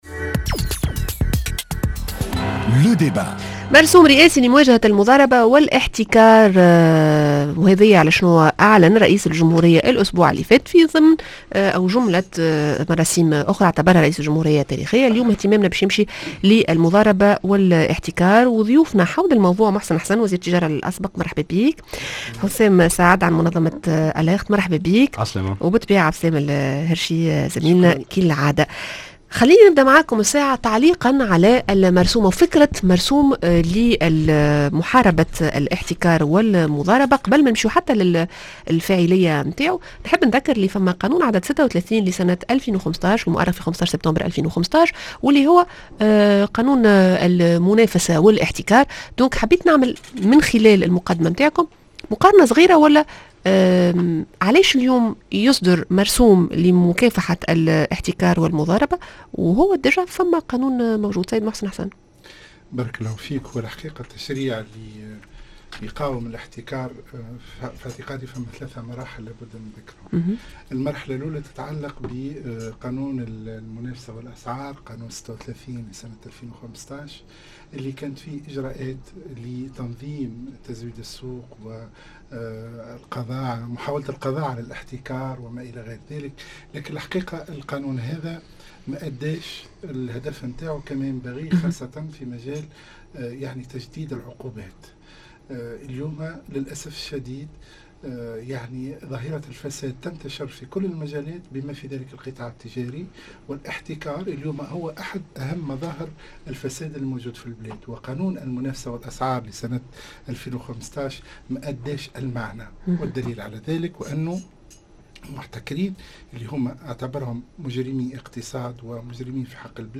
Le débat